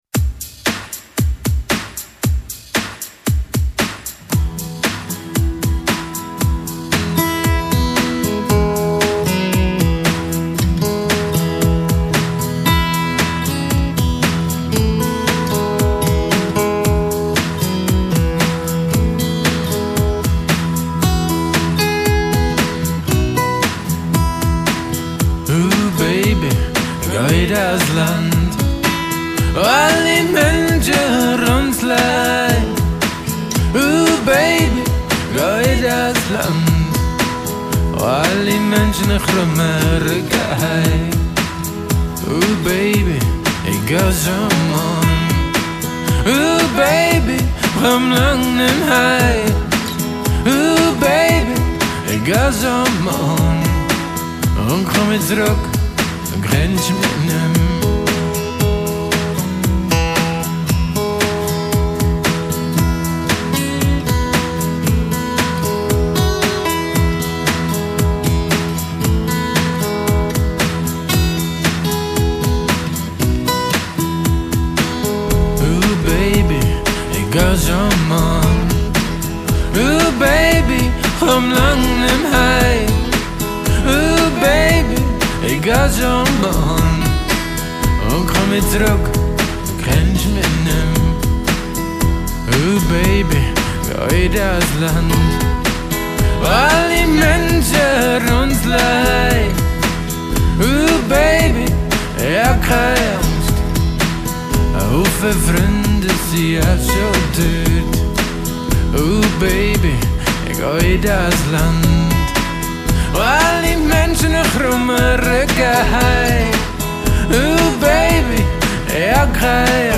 Aufnahmejahr: 2000; Homerecording
Akustische Gitarren